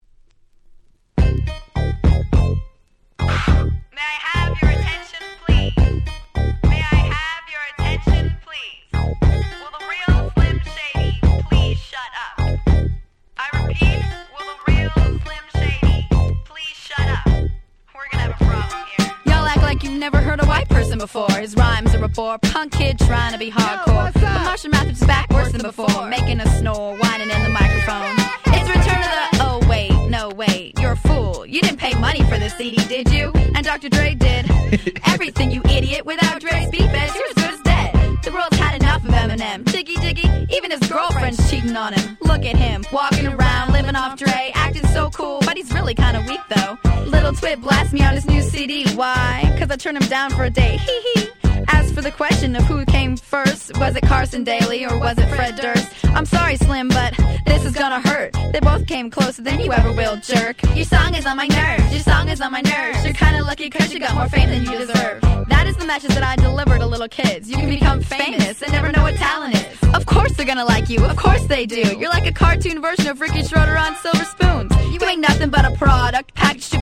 White Press Only Mash Ups !!